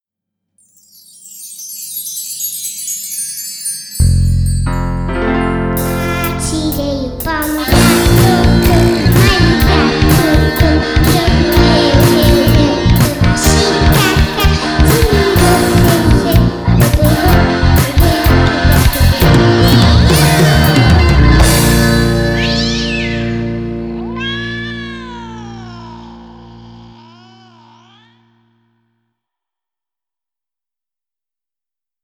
Baixo, guitarra, piano, bateria e percussão